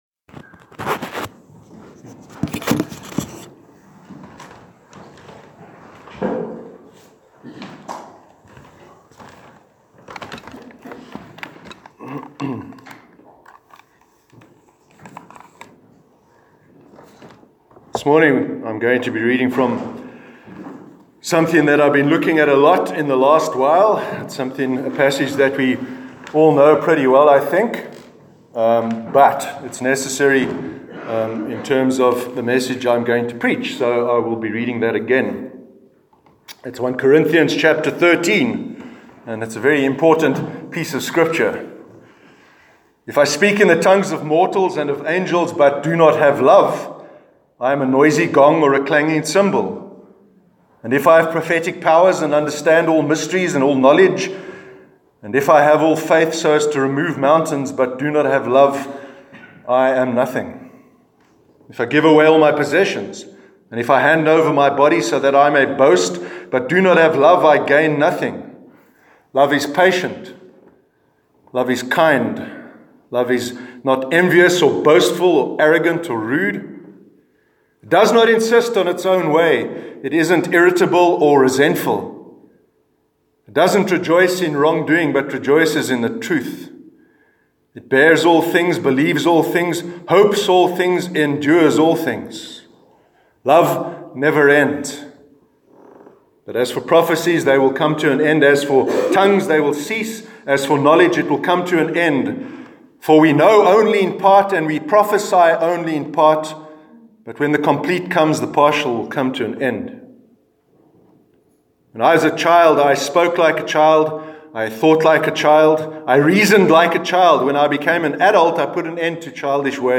Sermon on Relationships- 1st July 2018
sermon-1st-july-2018.mp3